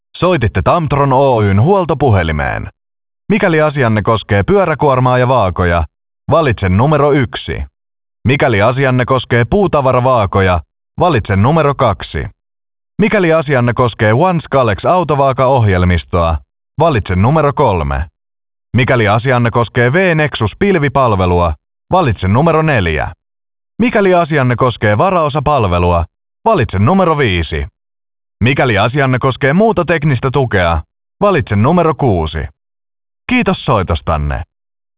Junge, Cool, Corporate
Telefonie